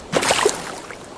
splash_high.wav